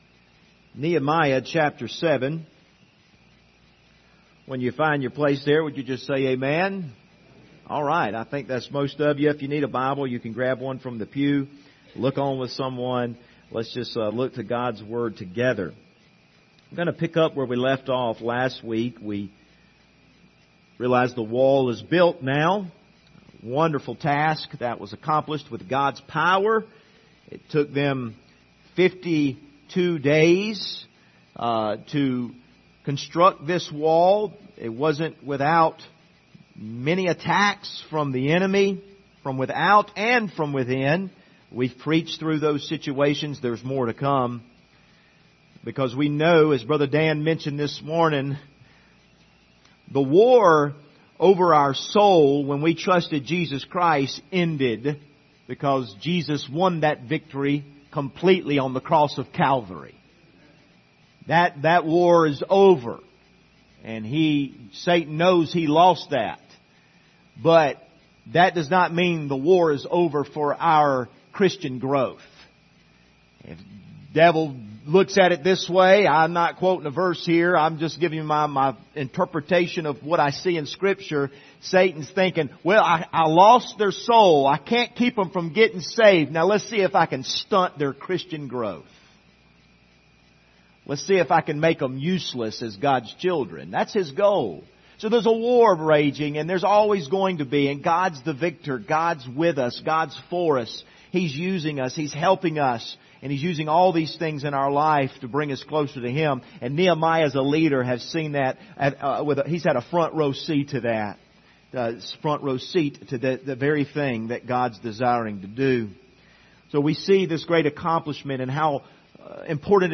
Passage: Nehemiah 7:4-6 Service Type: Sunday Morning